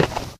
default_metal_footstep.3.ogg